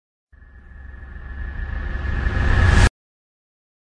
Descarga de Sonidos mp3 Gratis: miedo 14.